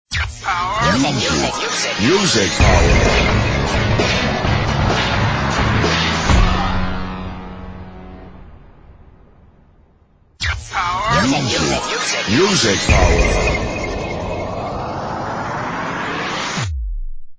Section#2-Jingles, music logos
All tracks encoded in mp3 audio lo-fi quality.